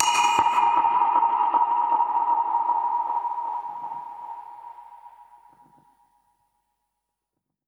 Index of /musicradar/dub-percussion-samples/125bpm
DPFX_PercHit_A_125-12.wav